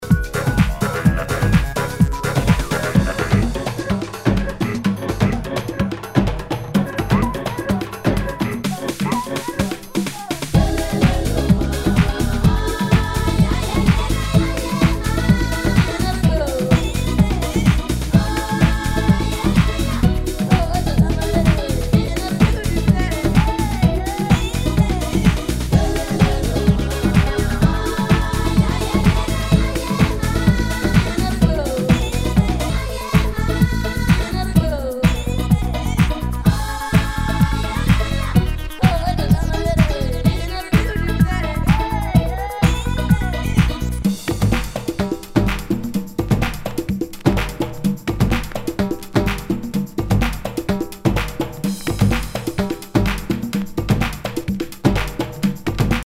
HOUSE/TECHNO/ELECTRO
ナイス！トライバル・ハウス！